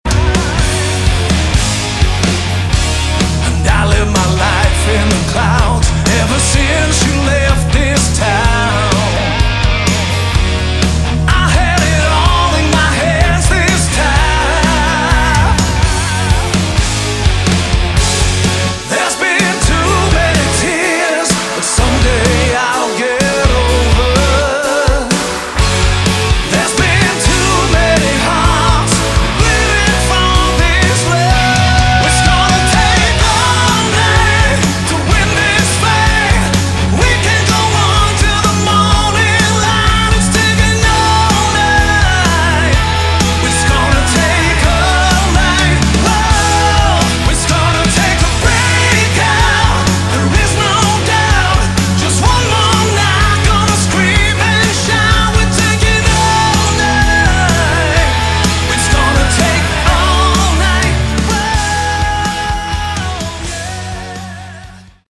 Category: Melodic Rock / AOR
Lead Vocals
Keyboards
Guitar
Bass
Drums
This is utter perfection of hard AOR the Scandinavian way.